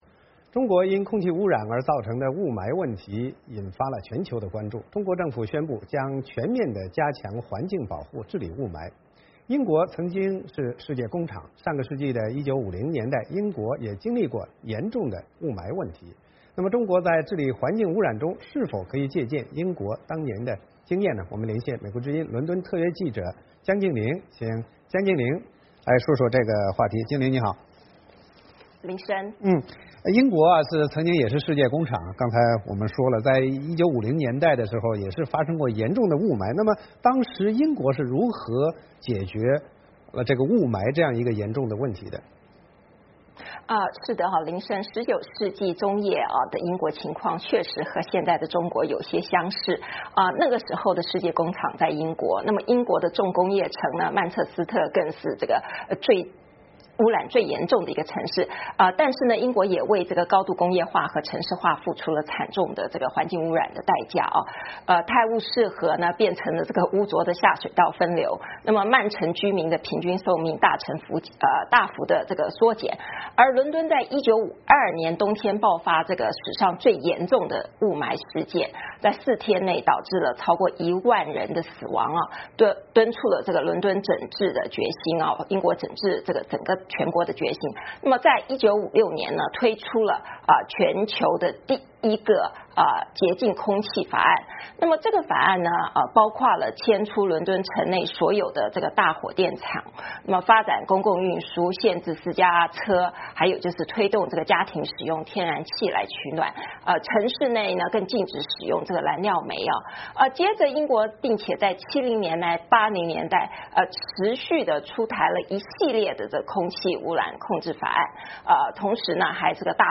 VOA连线：治理雾霾 ，中国能向英国学什么？